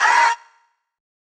[ARDIST] Vox - Impossible.wav